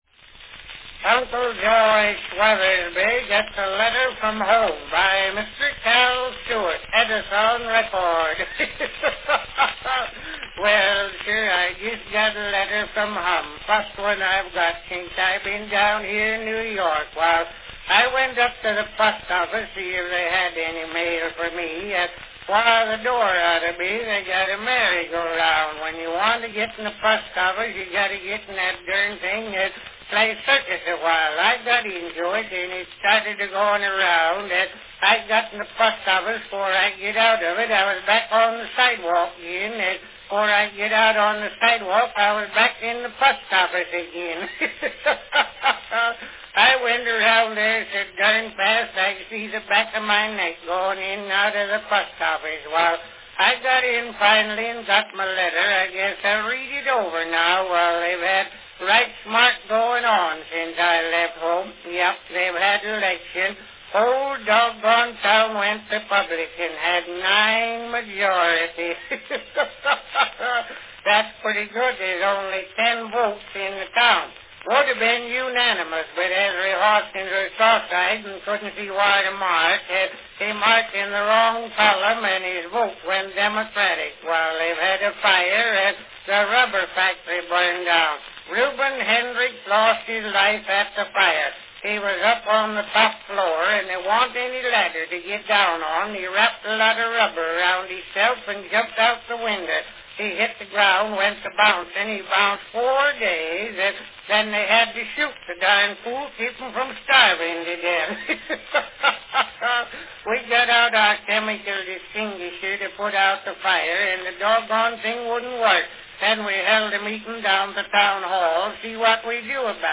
From 1909, enjoy a humorous monolog by Cal Stewart as he presents Uncle Josh's Letter From Home.
Company Edison's National Phonograph Company
Category Talking
Announcement "Uncle Josh Weathersby gets a letter from home, by Mr. Cal Stewart.  Edison record."